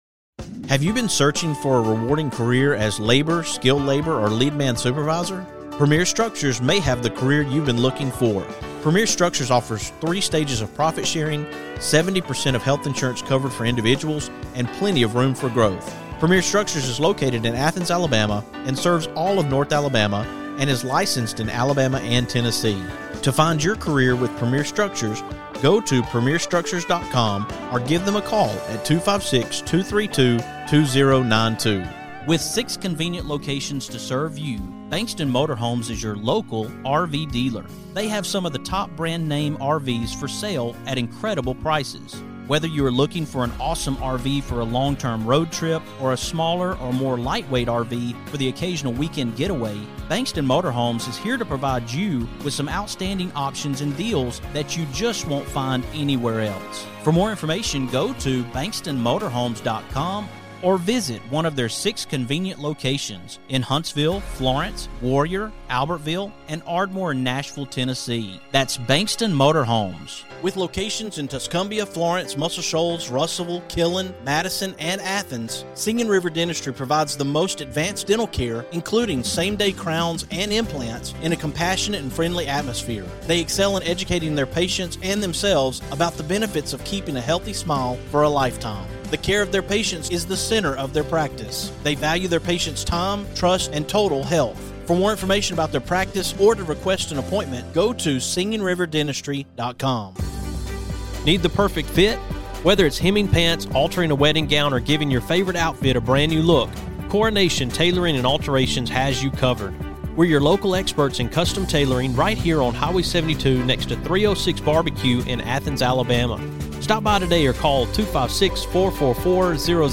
On this edition of TMWS, I have two music guests joining me.